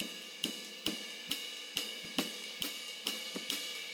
Closed Hats
RIDE_LOOP_10.wav